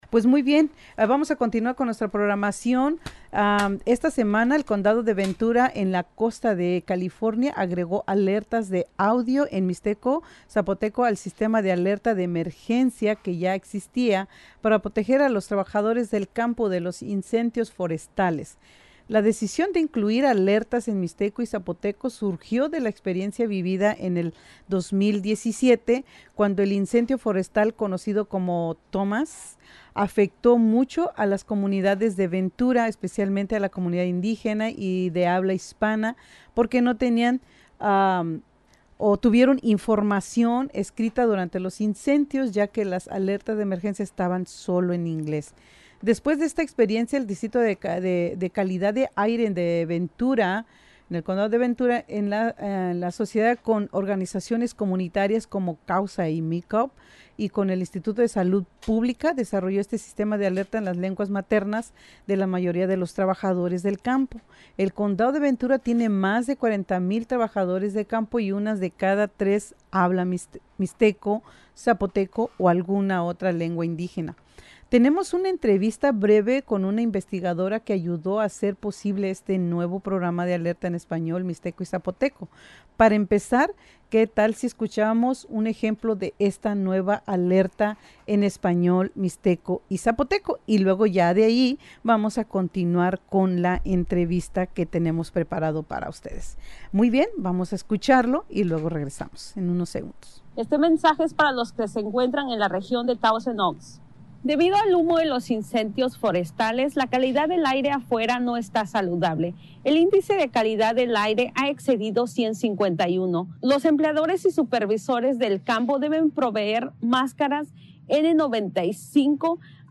Listen to the interview in both Mixteco and Spanish on Radio Bilingue’s “Hora Mixteca Show”